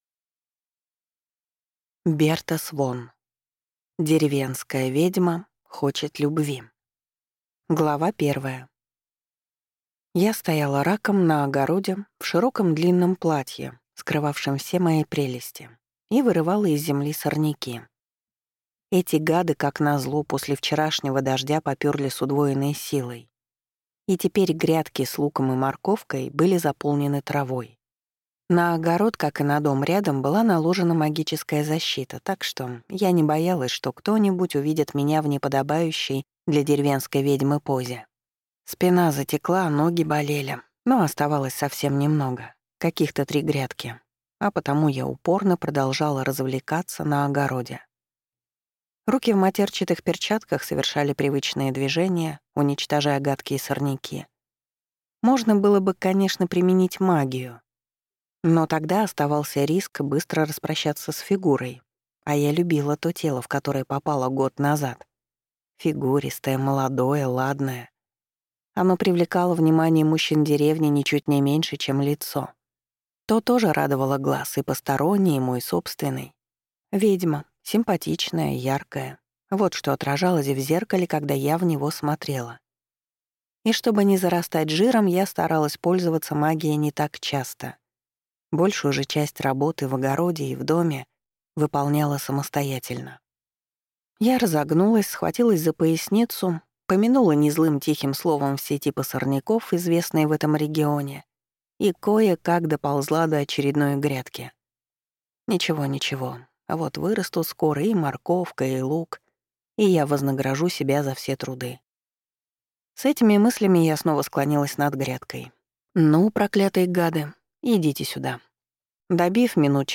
Аудиокнига Деревенская ведьма хочет любви | Библиотека аудиокниг